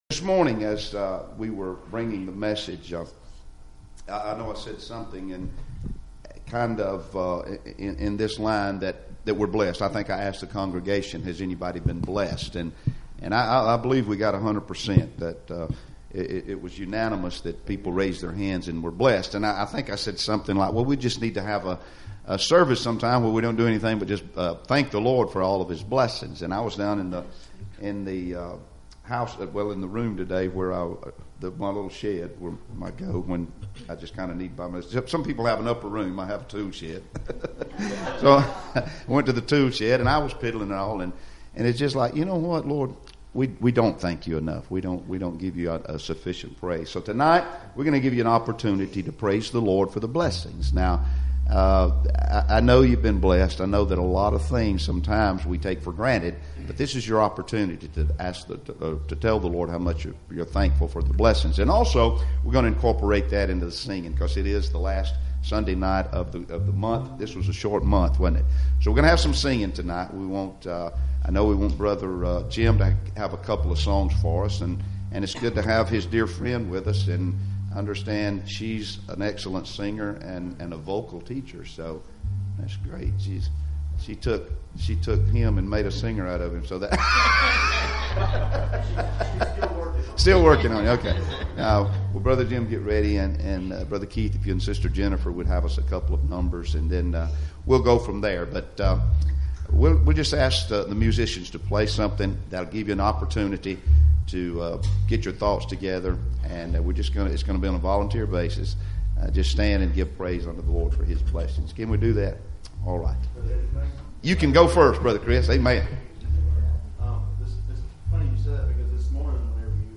Praise Service